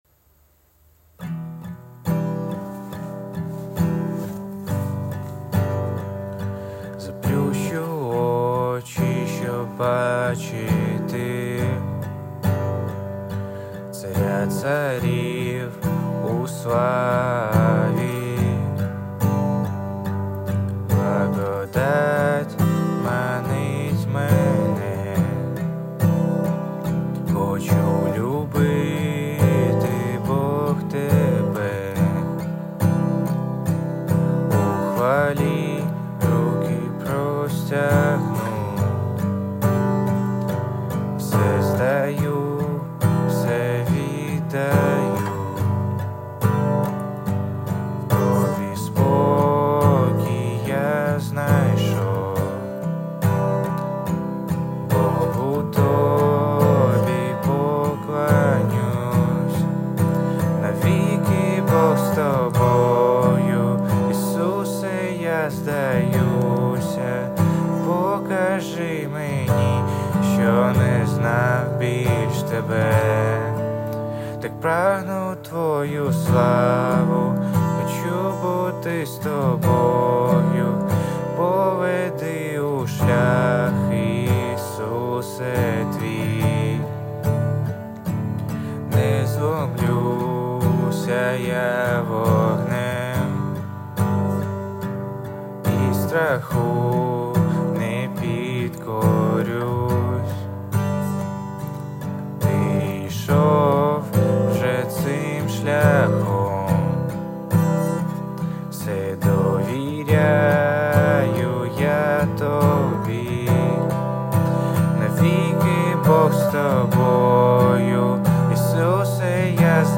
86 просмотров 29 прослушиваний 0 скачиваний BPM: 135